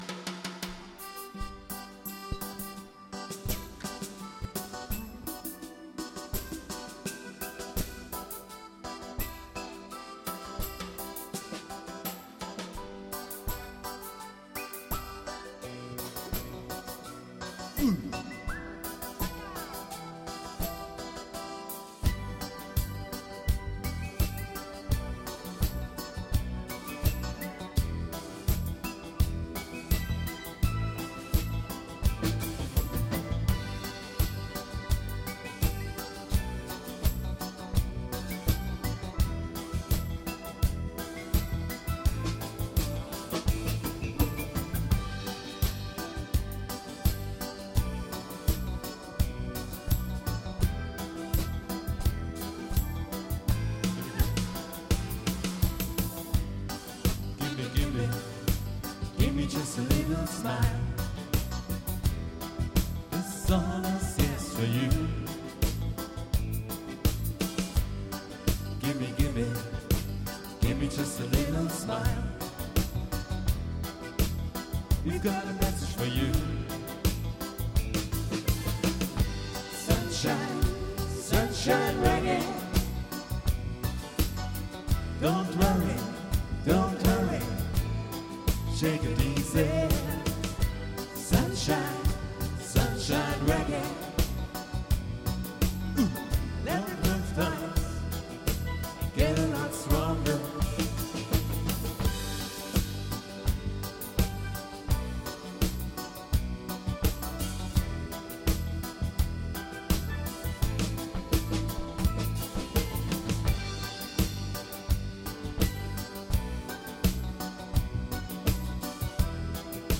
• Coverband